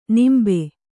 ♪ nimbe